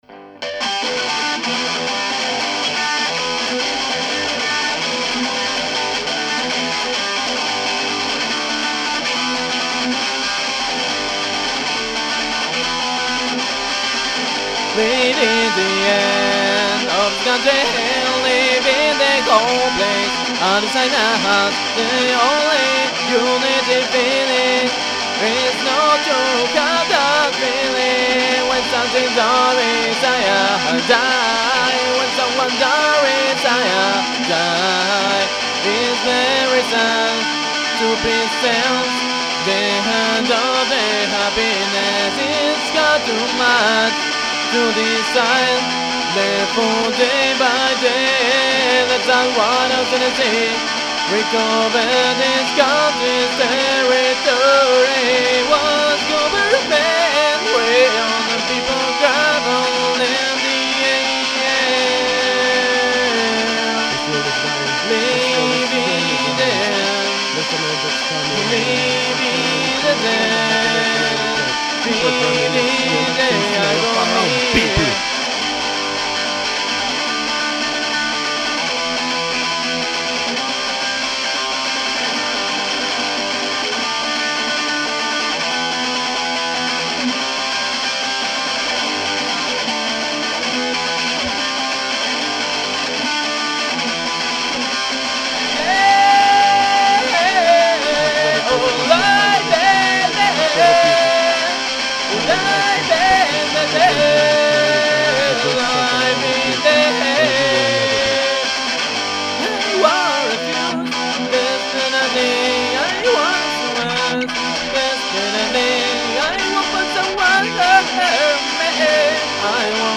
here's a guitar and voice version of it
The second mic was very low and the speech is almost imposible to hear, but it's there. It the thing that sounds like a radio preacher on the backgroud :P
The total spech time must be around the 30 seconds and is said behind the actual singing.